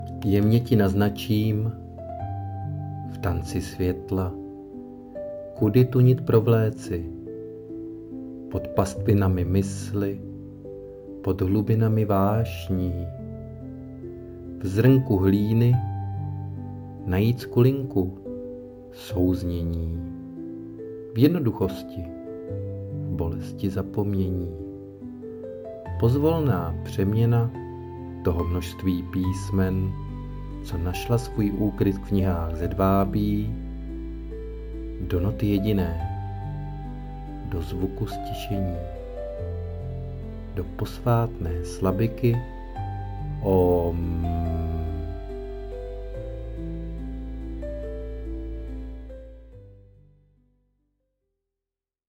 hudba: AI (by SUNO)